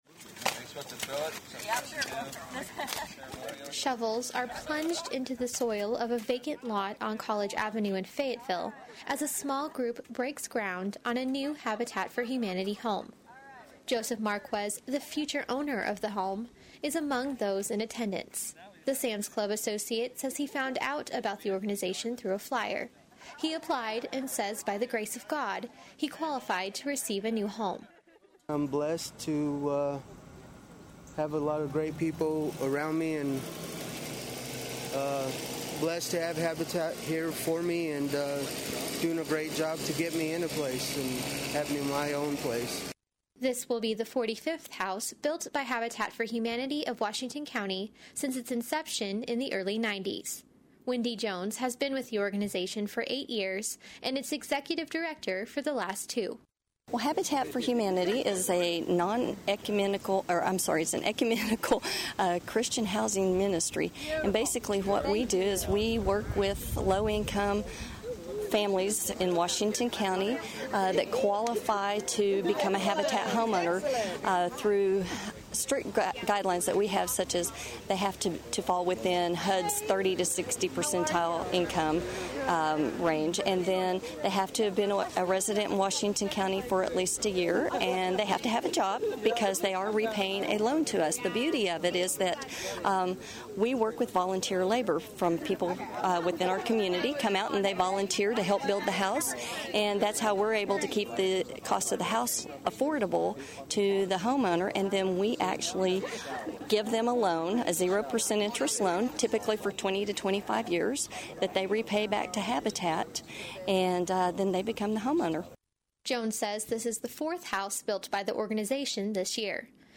Last Friday, Habitat for Humanity of Washington County celebrated the upcoming construction of a new house with a groundbreaking ceremony.
Habitat Groundbreaking.mp3